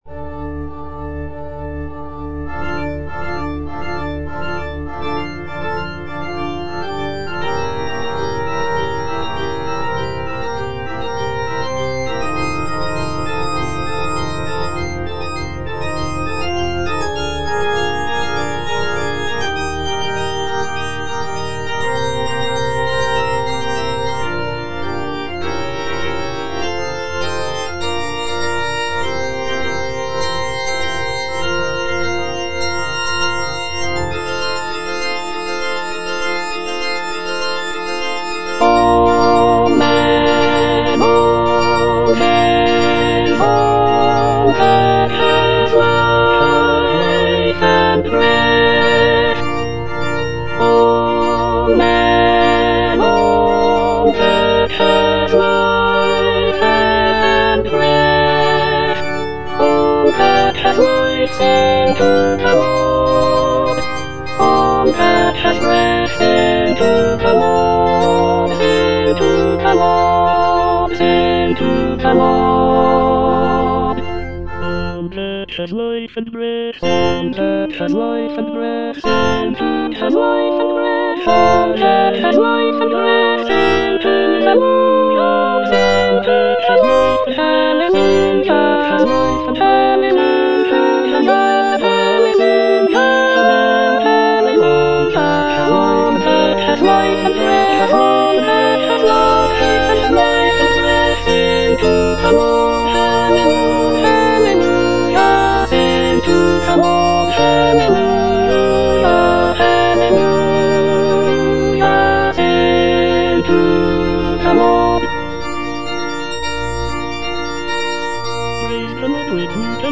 F. MENDELSSOHN - HYMN OF PRAISE (ENGLISH VERSION OF "LOBGESANG") All men, all things - Alto (Emphasised voice and other voices) Ads stop:
"Hymn of Praise" is a choral symphony composed by Felix Mendelssohn in 1840.
The piece features a large orchestra, chorus, and soloists, and is divided into three parts that include a mixture of orchestral and vocal movements.